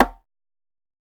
Perc [Dro Rimshot].wav